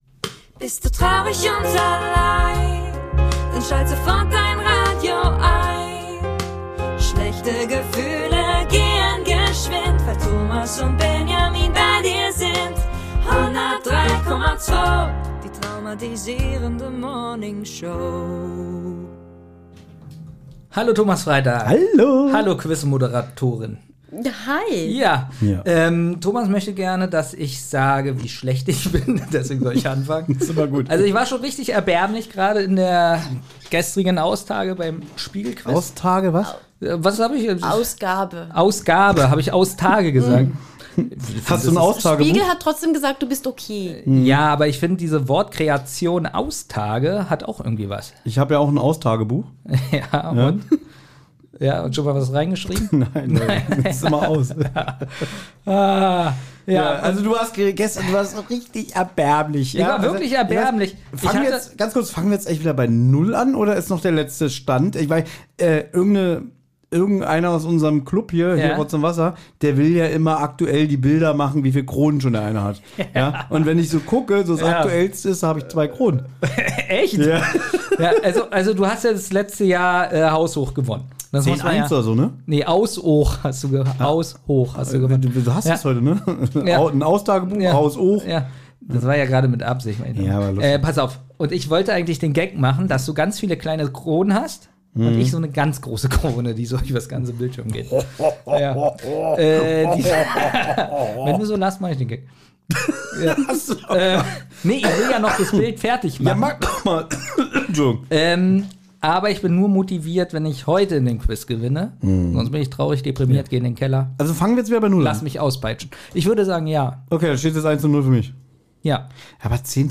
haben wir wieder eine Moderatorin dabei, die die Fragen vorliest!